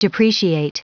Prononciation du mot depreciate en anglais (fichier audio)
Prononciation du mot : depreciate